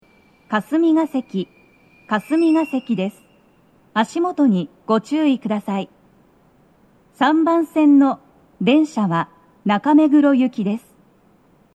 足元注意喚起放送が付帯されており、粘りが必要です。
到着放送1
hkasumigaseki3toucyaku.mp3